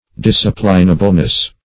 Search Result for " disciplinableness" : The Collaborative International Dictionary of English v.0.48: Disciplinableness \Dis"ci*plin*a*ble*ness\, n. The quality of being improvable by discipline.